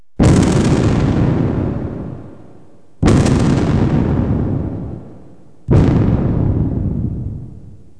[sound button] cannons firing
mortars.wav